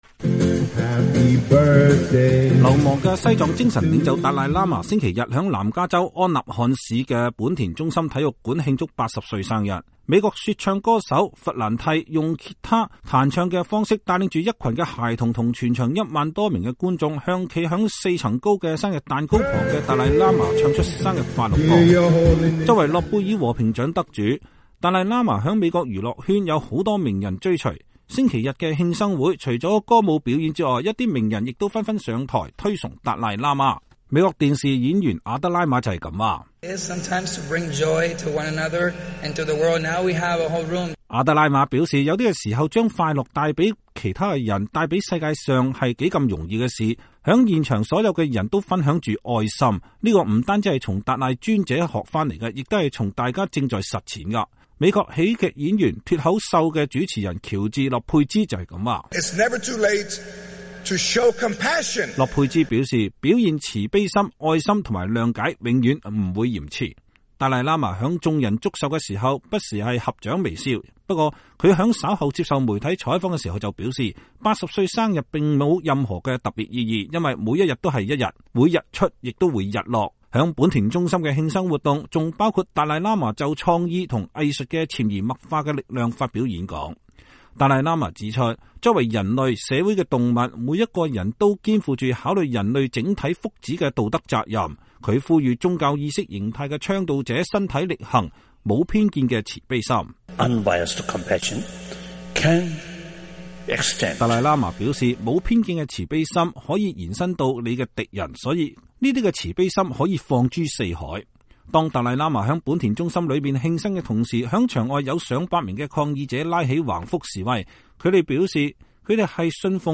流亡的西藏精神領袖達賴喇嘛星期天在南加州安納罕市的本田中心體育館慶祝八十歲生日。美國說唱歌手弗蘭帝用吉他彈唱的方式，帶領一群孩童和全場一萬多名觀眾，向站在四層高的生日蛋糕旁的達賴喇嘛唱出生日快樂歌。